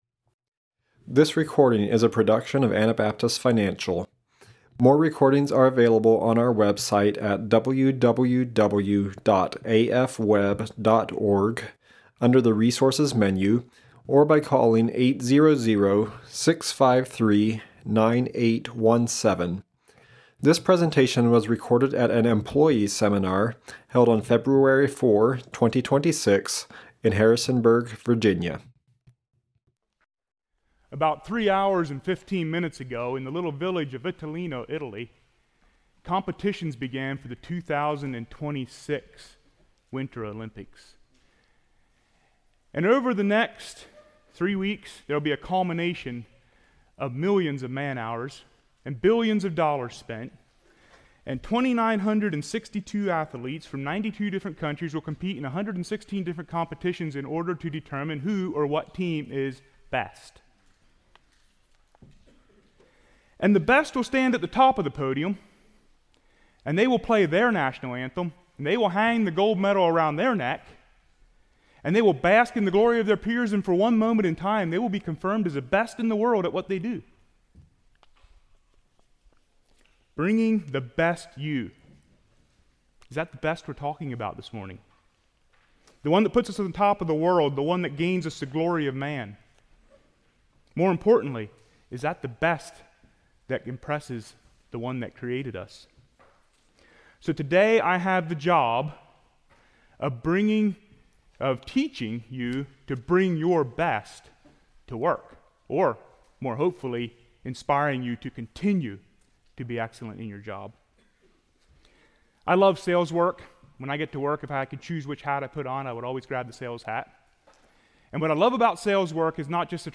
Virginia Employee Seminar 2026